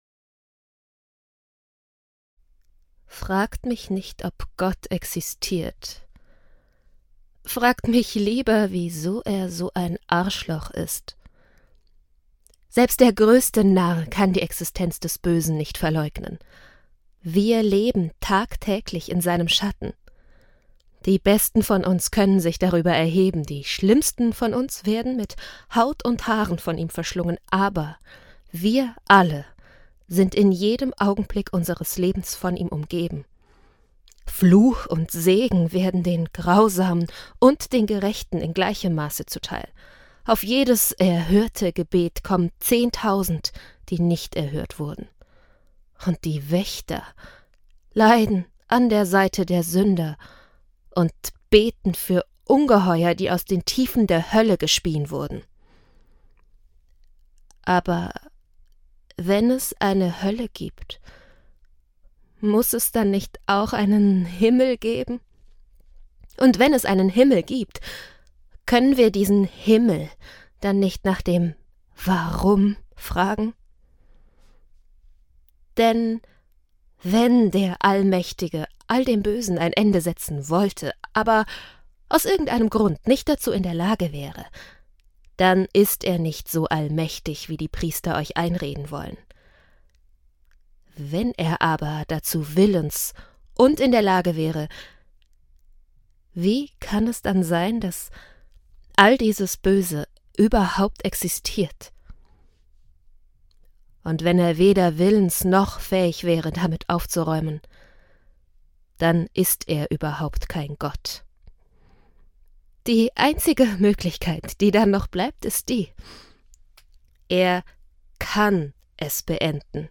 Warm, klar, einfühlsam – meine Stimme zieht Zuhörer*innen in ihren Bann, vermittelt Emotionen und hinterlässt Eindrücke, die bleiben.
Hörbuch Sample